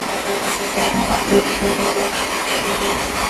Spirit Box Clip 2 Villisca Axe Murder House Spirit Box Clip 2 Another great response from a young female coming through our SB11 spirit box, this time in the downstairs area of the home. Same sweep rate and scanning as in clip #1. Only one word here, but it's clear and powerful! Young girl speaks downstairs This one is quite simple - "Daddy." show/hide spoiler Back to Villisca Axe Murder House Evidence Page